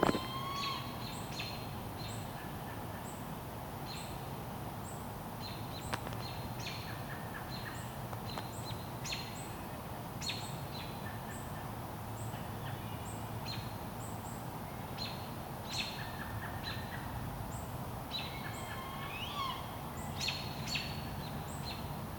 Famous for its “Who cooks for you? Who cooks for you-all?” hooting call.
Barred Owl:
recorded in New York, USA.